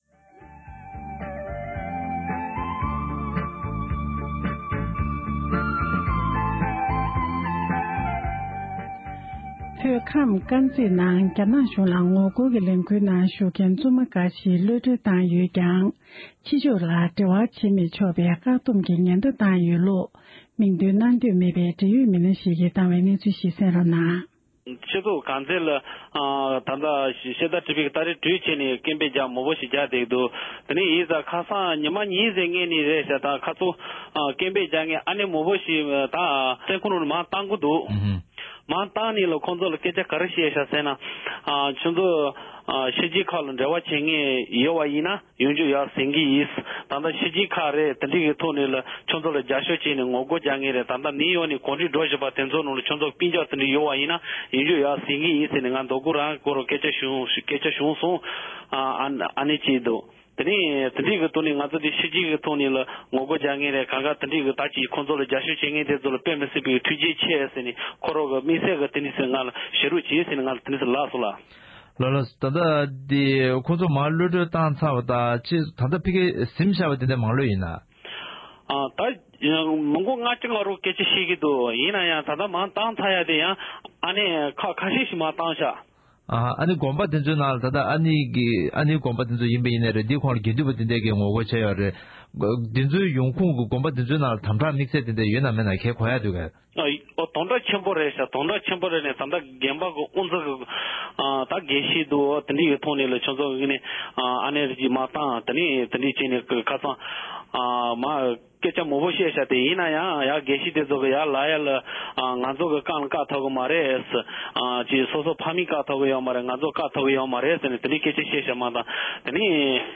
སྒྲ་ལྡན་གསར་འགྱུར།
༄༅༎རྒྱ་ནག་གཞུང་གིས་ཉེ་ཆར་བོད་ཁམས་དཀར་མཛེས་ཁུལ་དུ་ངོ་རྒོལ་གྱི་ལས་འགུལ་ནང་ཞུགས་མཁན་བཙུན་མ་ཁག་གློད་བཀྲོལ་བཏང་ཡོད་ཀྱང༌། ཕྱི་ཕྱོགས་སུ་འབྲེལ་བ་བྱེད་མི་ཆོག་པའི་ཉེན་བརྡ་བཏང་ཡོད་པའི་སྐོར། མིང་འདོན་གནང་འདོད་མེད་པའི་བོད་མི་ཞིག་གིས་འདི་ག་ཨེ་ཤེ་ཡ་རང་དབང་རླུང་འཕྲིན་ཁང་ལ་ཁུངས་ལྡན་གྱི་གནས་ཚུལ་ངོ་སྤྲོད་གནང་བར་གསན་རོགས་ཞུ༎